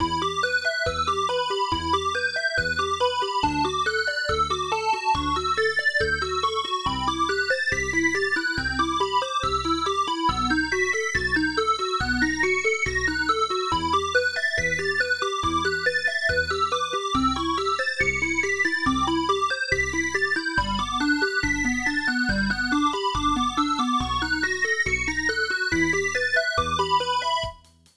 Result: Drum velocities set to 100, hi-hats at 70
8-bit-game-soundtrack_velocities.wav